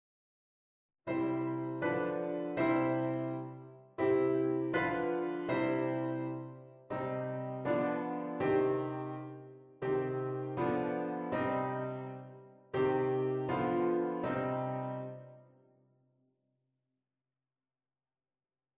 VII6 als wisselakkoord in een tonica-prolongatie: